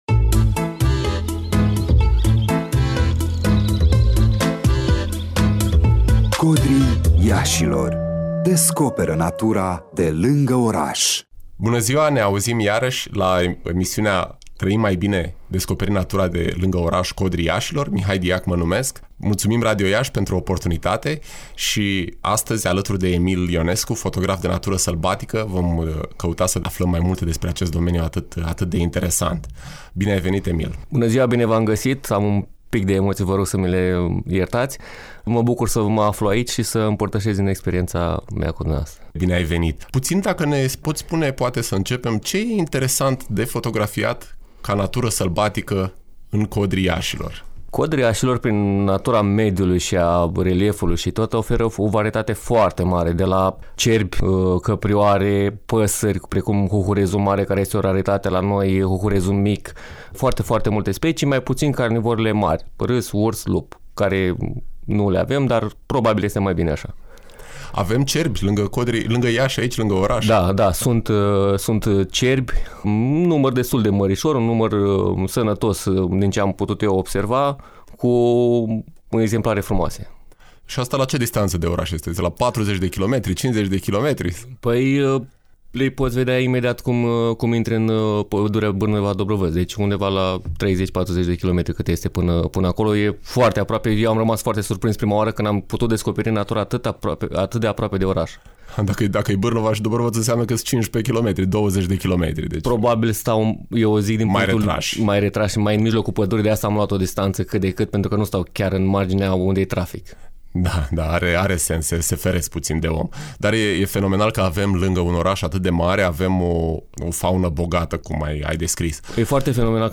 fotograf de natură sălbatică.